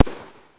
shot.ogg